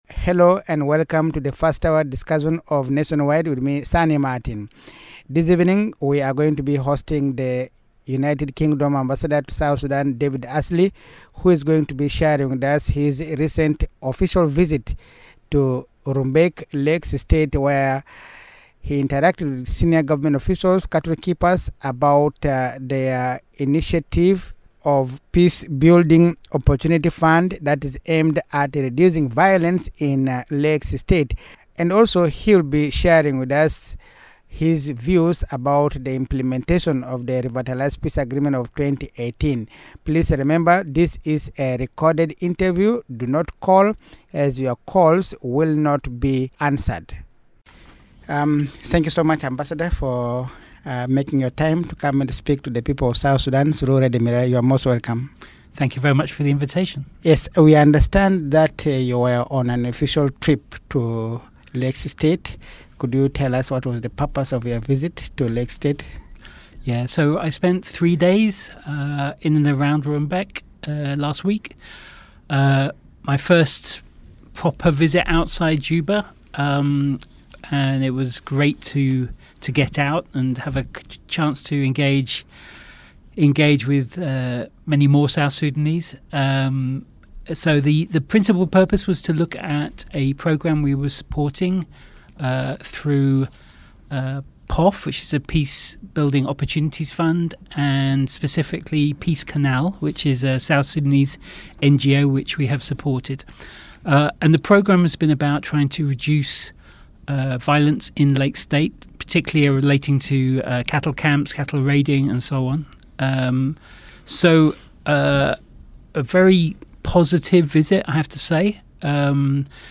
NATIONWIDE: A Conversation with U.K. Ambassador David Ashley on Peace and Development in South Sudan